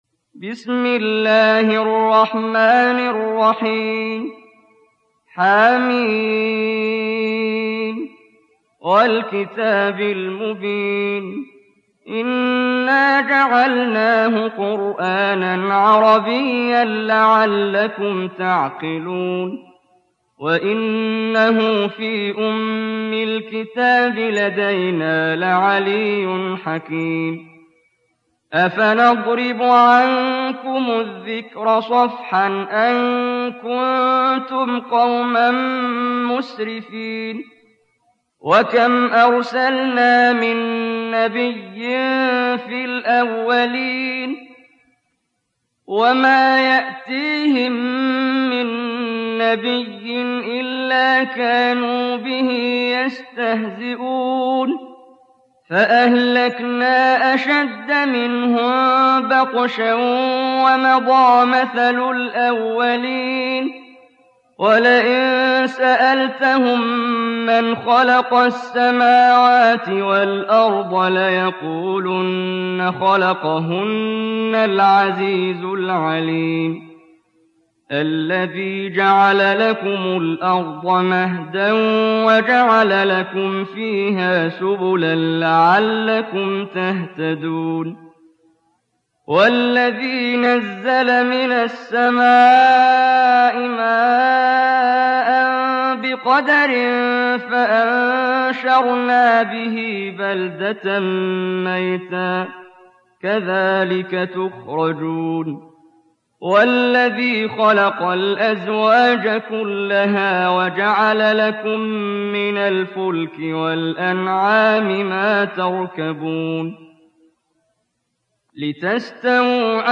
دانلود سوره الزخرف mp3 محمد جبريل روایت حفص از عاصم, قرآن را دانلود کنید و گوش کن mp3 ، لینک مستقیم کامل